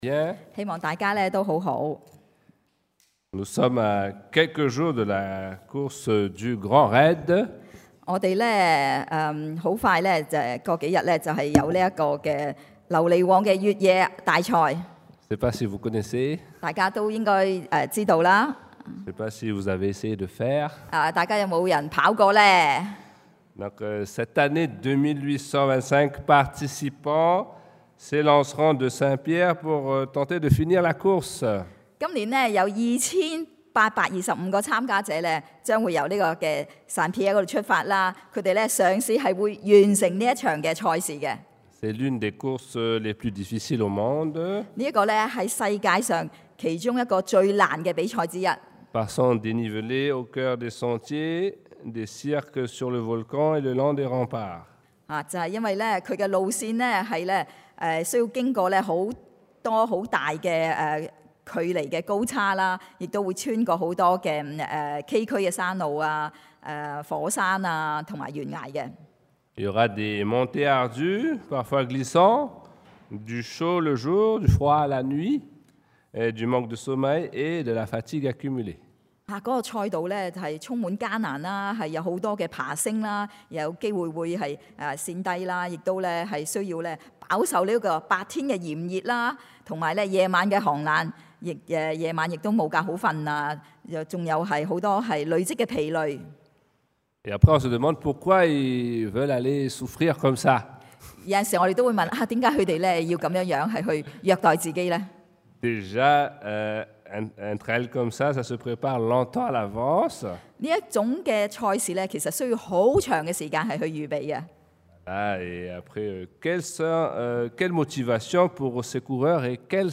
2 Tim 提摩太后书 2:8-15 Type De Service: Predication du dimanche « Croire et obéir 信靠順服 La loi de l’Éternel est parfaite